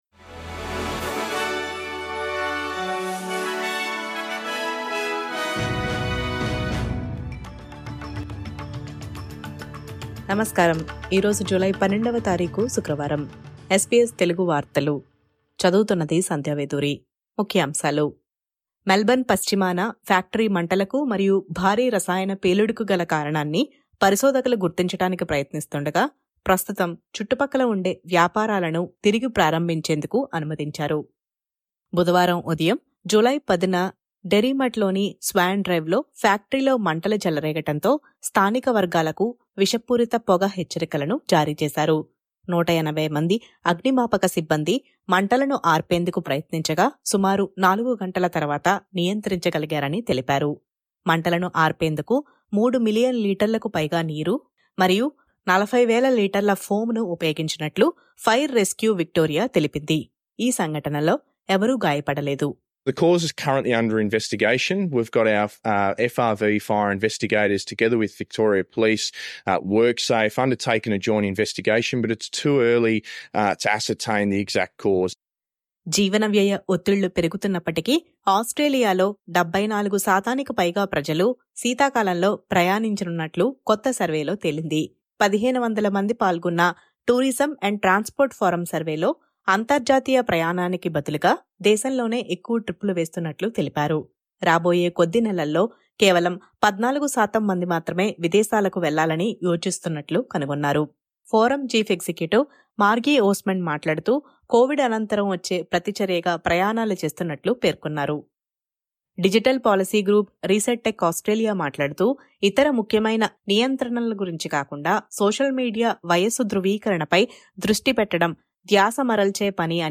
SBS తెలుగు 12/07/24 వార్తలు: సోషల్ మీడియాకు 16 సంవత్సరాల వయస్సు పరిమితి పెట్టాలన్న కూటమి ప్రతిపాదన
SBS తెలుగు వార్తలు.